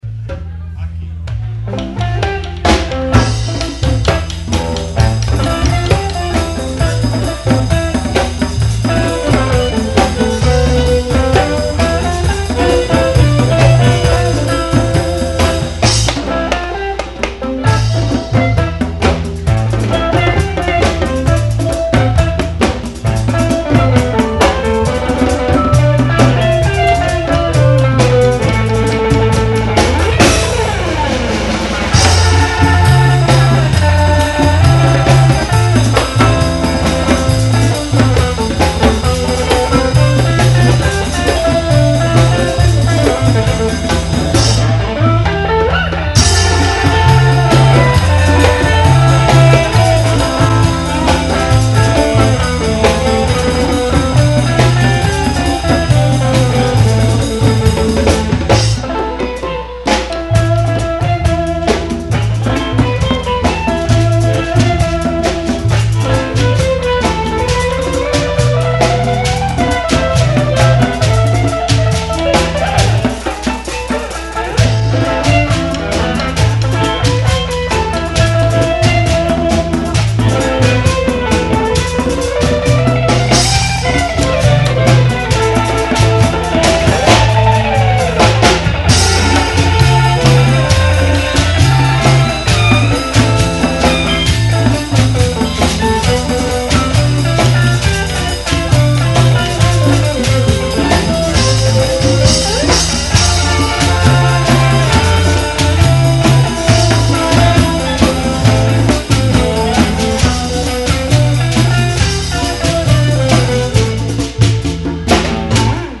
recorded live at the Second Street Brewery in Santa Fe